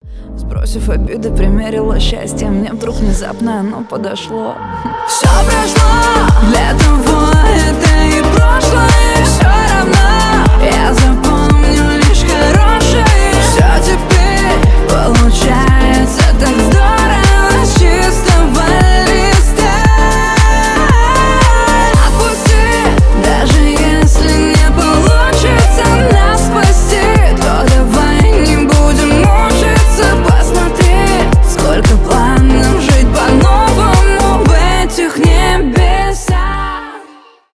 счастливые , поп